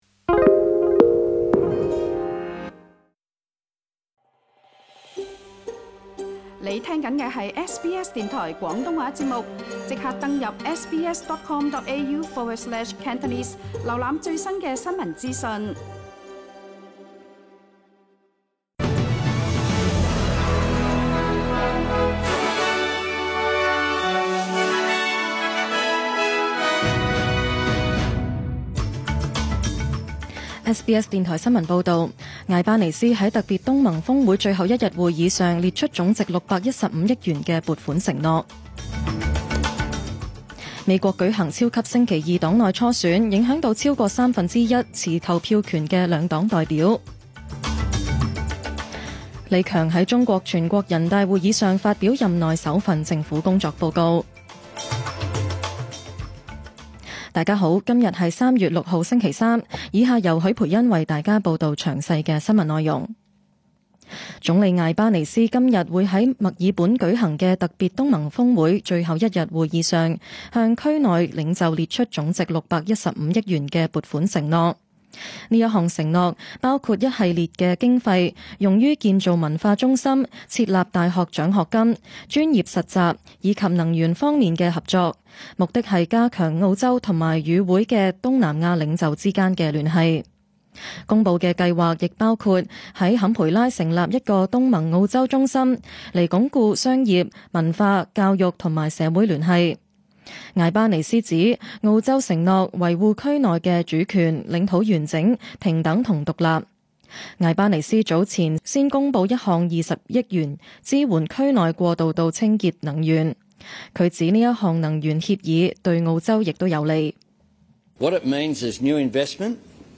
SBS 中文新聞 （3月6日） 12:47 SBS 廣東話節目中文新聞 SBS廣東話節目 View Podcast Series Follow and Subscribe Apple Podcasts YouTube Spotify Download (11.71MB) Download the SBS Audio app Available on iOS and Android 請收聽本台為大家準備的詳盡早晨新聞。 新聞提要 艾巴尼斯在「特別東盟峰會」最後一日會議上列出總值615億元撥款承諾。 美國舉行超級星期二黨內初選，影響到三份之一以上持投票權的兩黨代表。 李強在中國全國人大會議上發表任內首份政府工作報告。